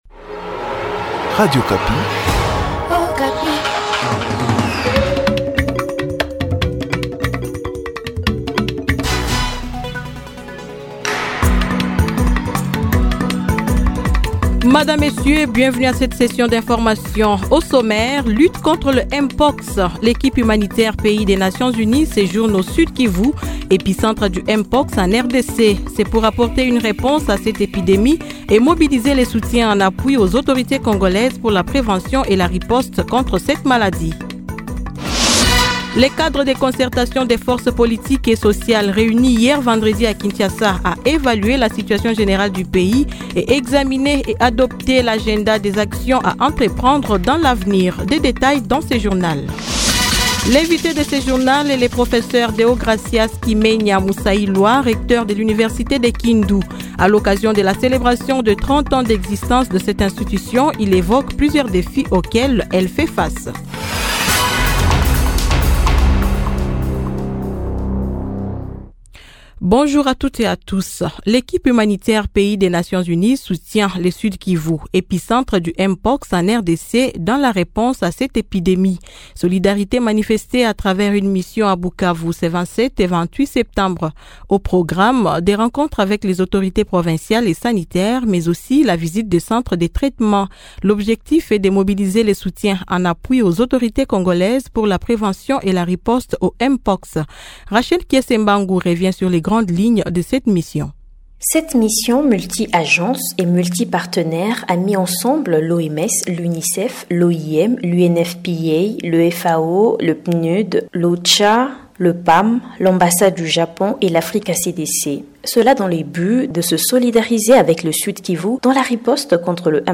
Journal matin 07H-08H